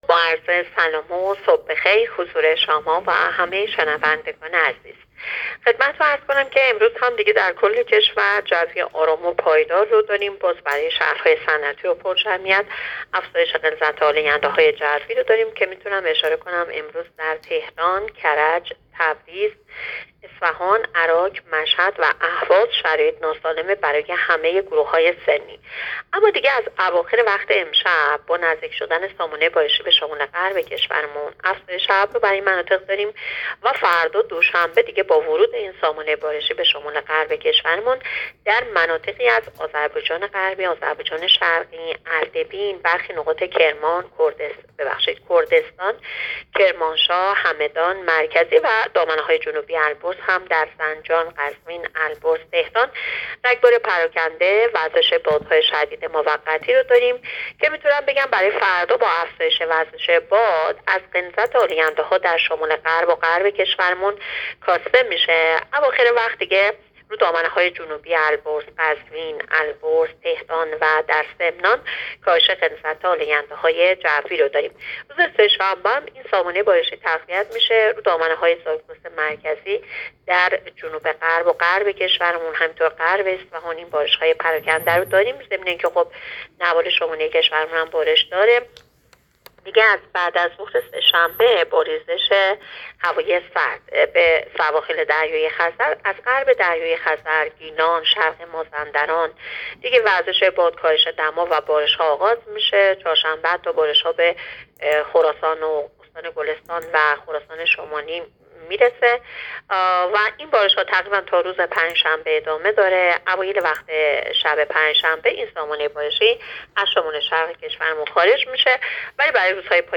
گزارش رادیو اینترنتی پایگاه‌ خبری از آخرین وضعیت آب‌وهوای ۹ آذر؛